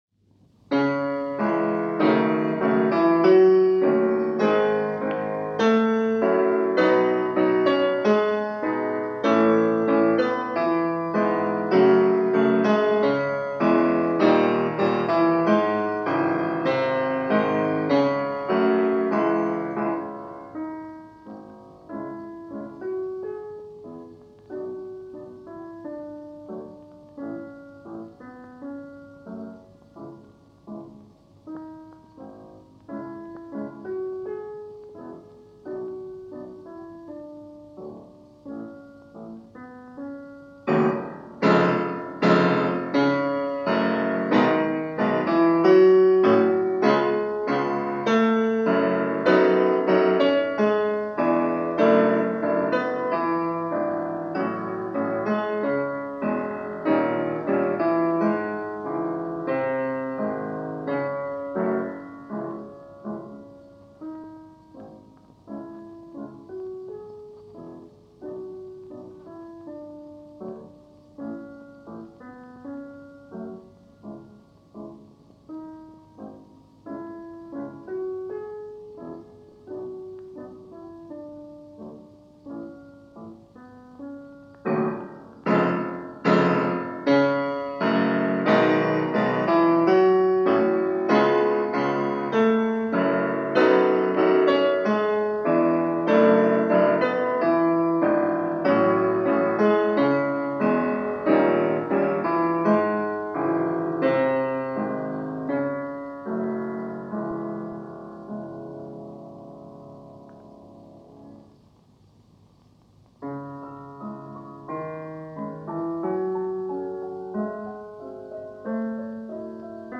Raymond Trouard – acclaimed as one of the greatest French pianists of the 20th Century.
piano